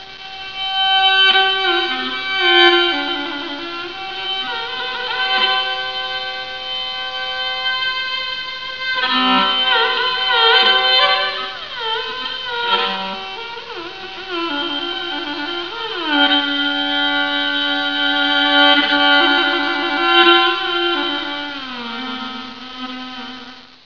馬頭琴の魅力たっぷりのCDです。